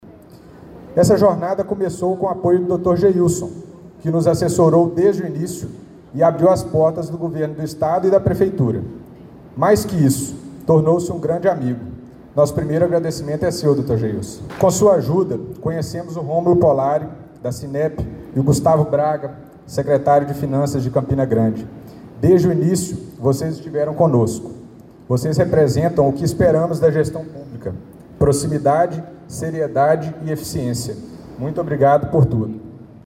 Governador e Prefeito de CG se encontram no evento de inauguração